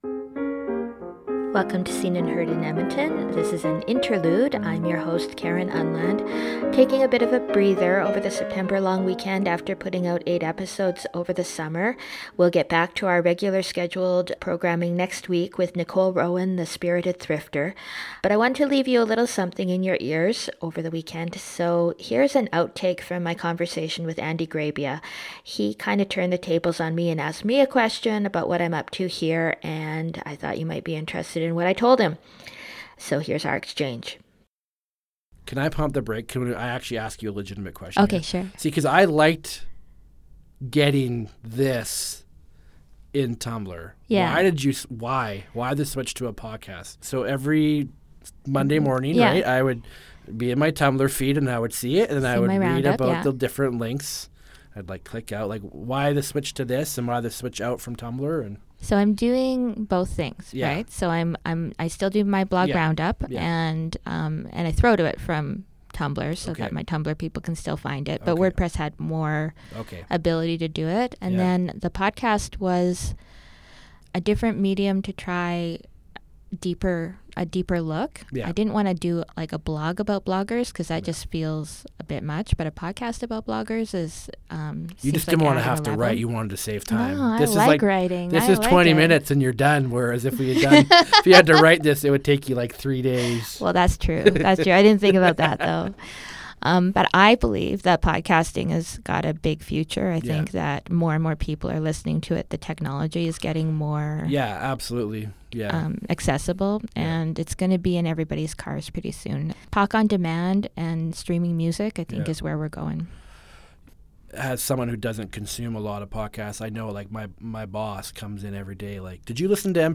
Intro and extro music: Beethoven’s Sonata No. 1 in F Minor, Op. 2 No. 1